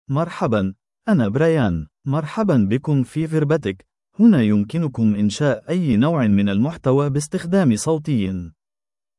MaleArabic (Standard)
BrianMale Arabic AI voice
Brian is a male AI voice for Arabic (Standard).
Listen to Brian's male Arabic voice.
Brian delivers clear pronunciation with authentic Standard Arabic intonation, making your content sound professionally produced.